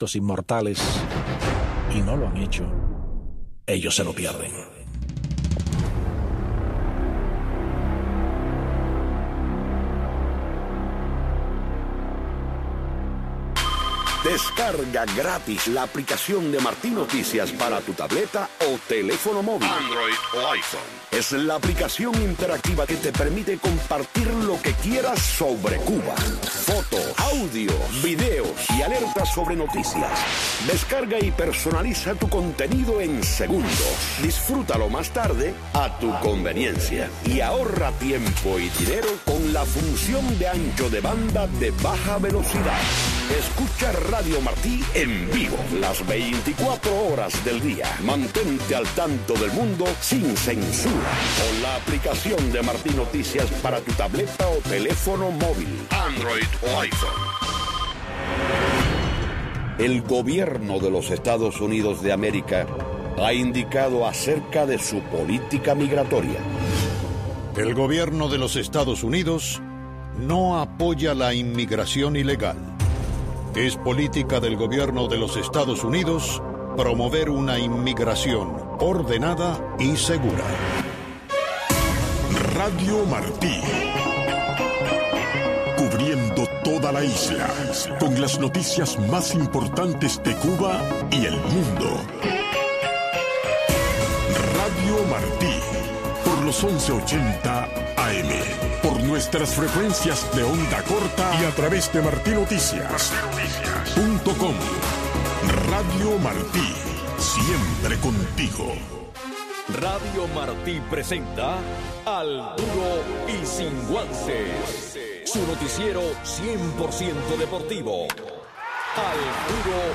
La misa dominical transmitida para Cuba desde el Santuario Nacional de Nuestra Señor de la Caridad, un templo católico de la Arquidiócesis de Miami dedicado a la Patrona de Cuba.